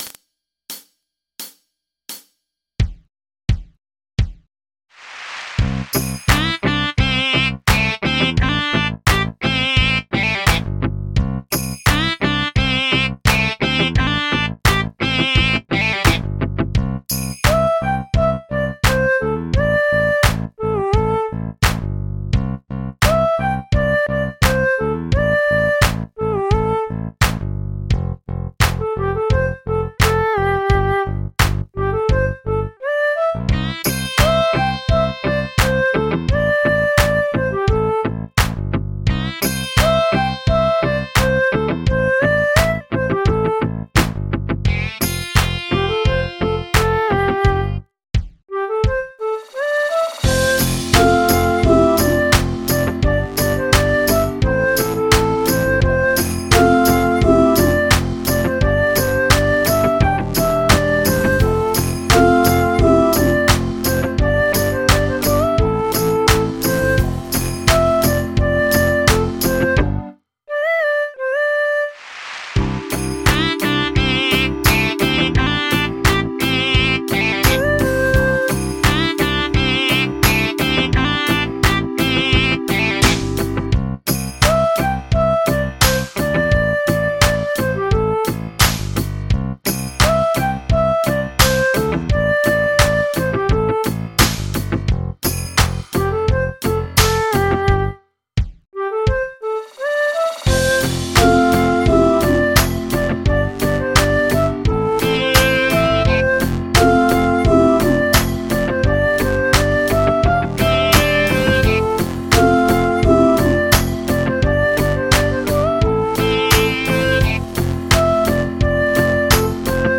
MIDI 42.39 KB MP3